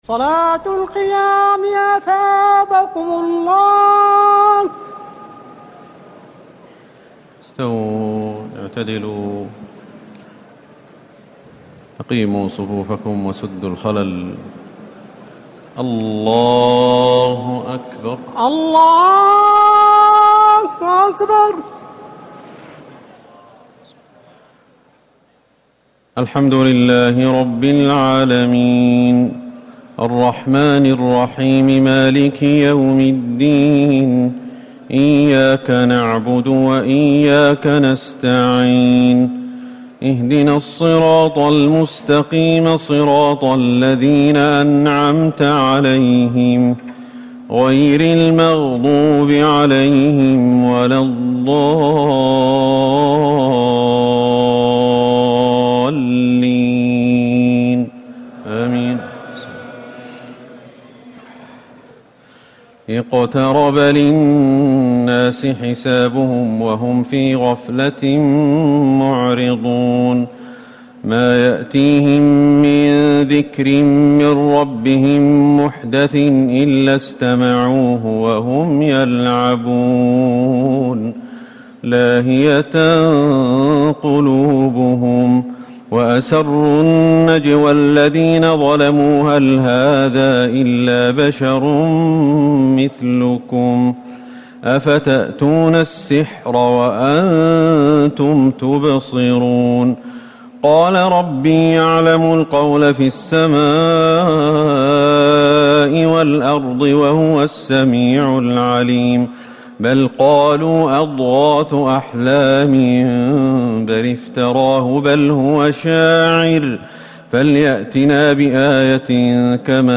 تراويح الليلة السادسة عشر رمضان 1439هـ سورة الأنبياء كاملة Taraweeh 16 st night Ramadan 1439H from Surah Al-Anbiyaa > تراويح الحرم النبوي عام 1439 🕌 > التراويح - تلاوات الحرمين